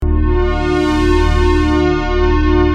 Free MP3 vintage Sequential circuits Pro-600 loops & sound effects 2